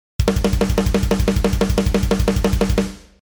2ビートやスラッシュビートを倍速にしたパターン（スネア裏打ち）
2ビートを倍速にしたパターンは、ブラックメタルっぽいですね。
ブラストビートのサンプル1
• ブラスト（2ビートの倍速）＝ブラックメタルっぽい雰囲気
blastbeat1.mp3